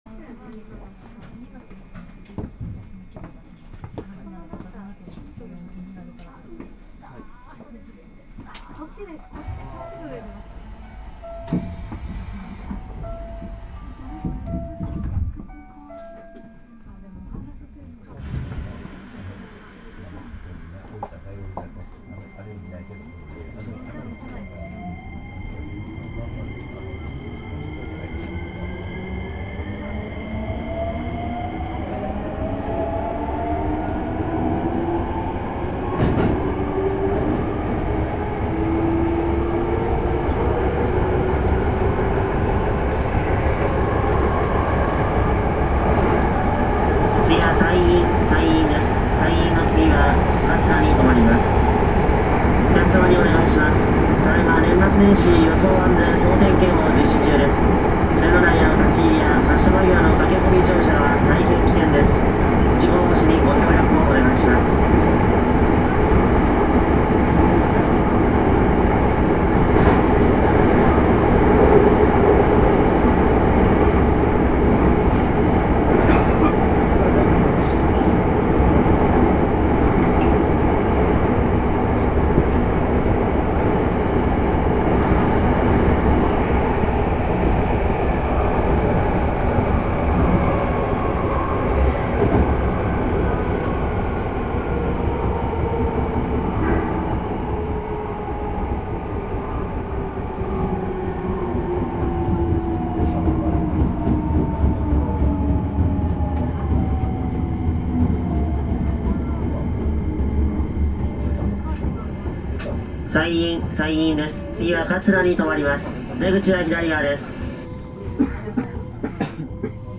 走行機器はIGBT素子によるVVVFインバータ制御で、定格200kWのTDK6126-A形かご形三相交流誘導電動機を制御します。
走行音
録音区間：大宮～西院(通勤特急)(お持ち帰り)